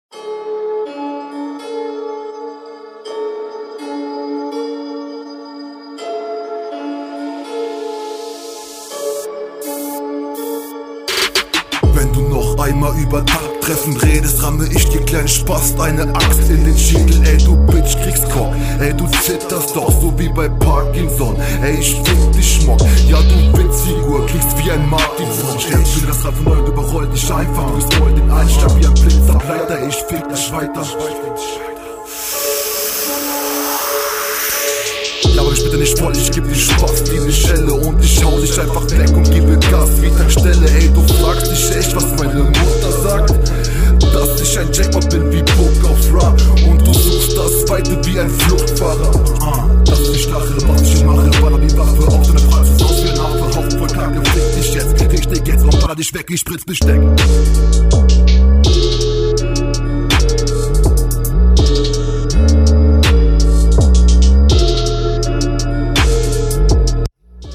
Stimme kommt hier viel mehr in die Fresse aber die Abmische ist Katastrophe. Unsynchron.